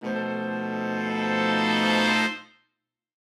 Index of /musicradar/gangster-sting-samples/Chord Hits/Horn Swells
GS_HornSwell-B7b2b5.wav